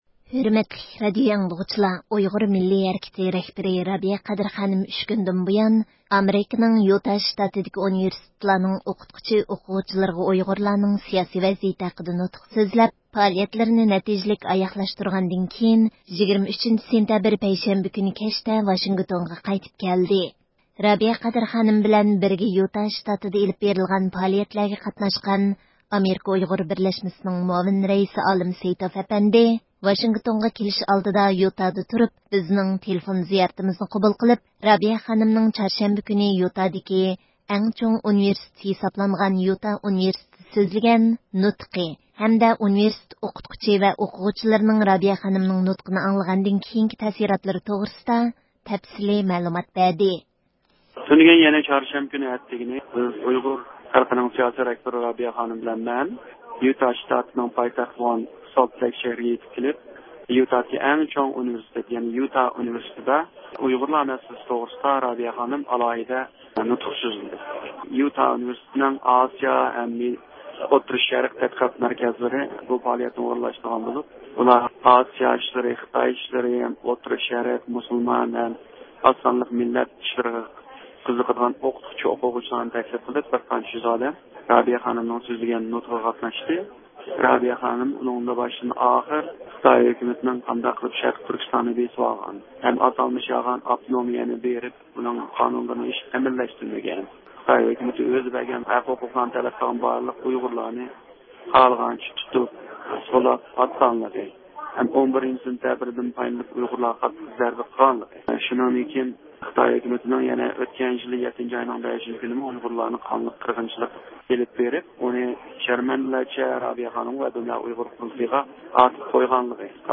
بىزنىڭ تېلېفون زىيارىتىمىزنى قوبۇل قىلىپ